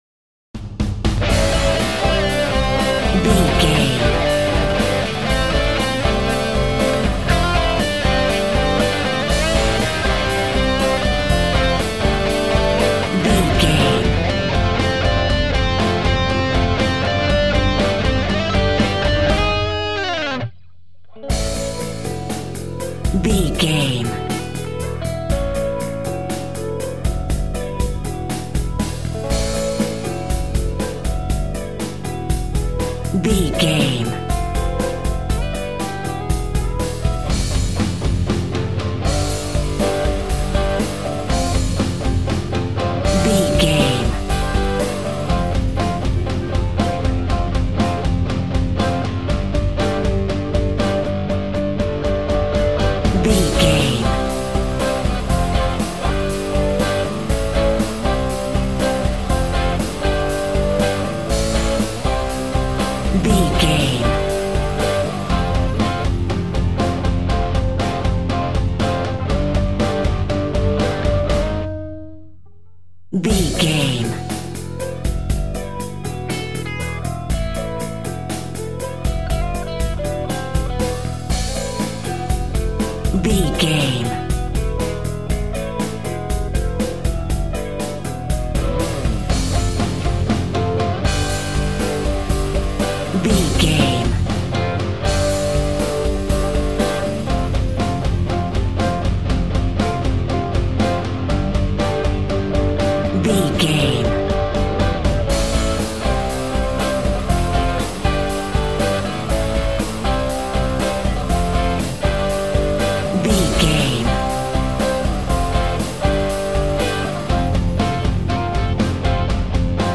Grunge Rock.
Epic / Action
Mixolydian
Fast
heavy rock
distortion
hard rock
Instrumental rock
drums
bass guitar
electric guitar
piano
hammond organ